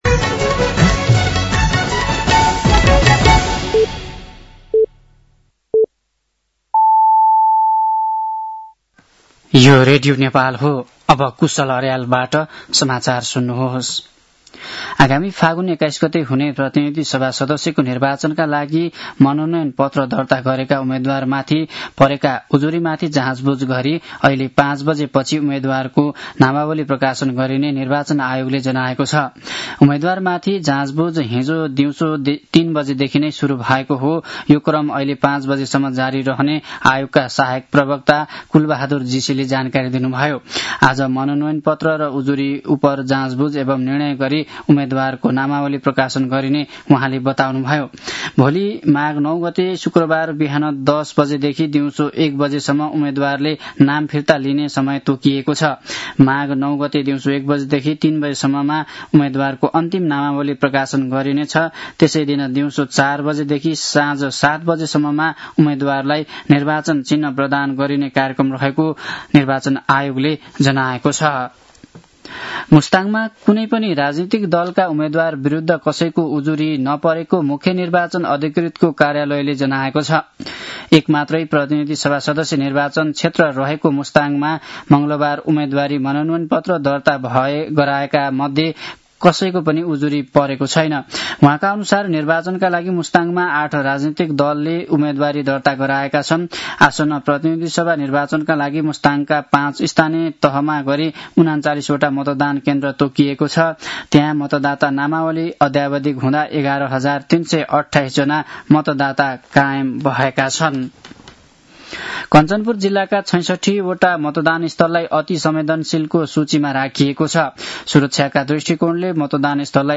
साँझ ५ बजेको नेपाली समाचार : ८ माघ , २०८२
5.-pm-nepali-news-1-9.mp3